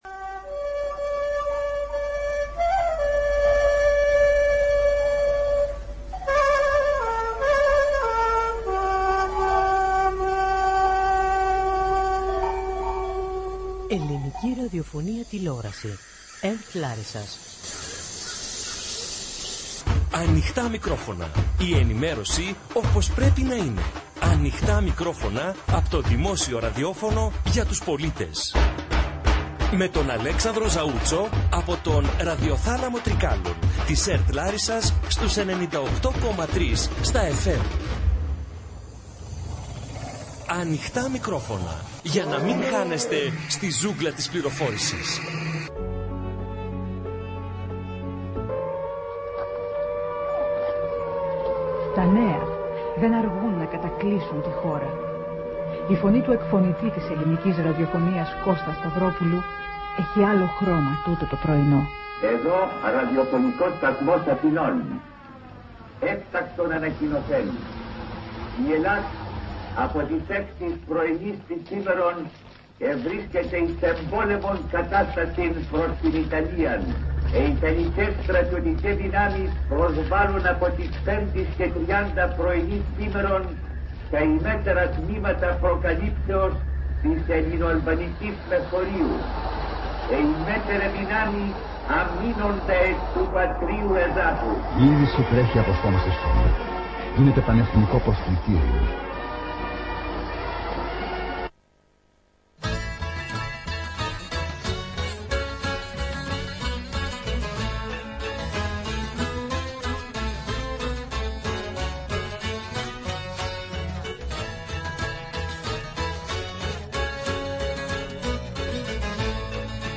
από τον Ραδιοθάλαμο Τρικάλων της ΕΡΤ Λάρισας (98,3 στα fm)